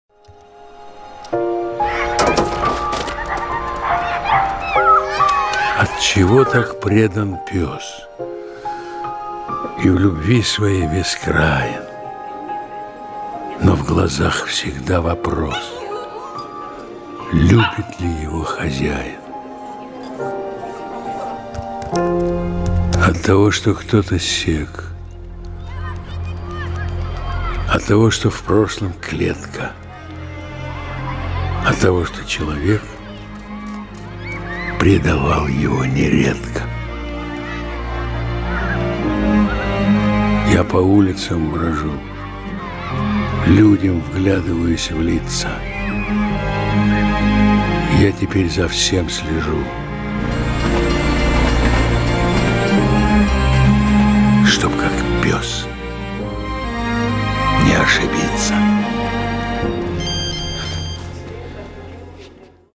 Несколько стихотворений в исполнении автора: